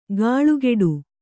♪ gāḷugeḍu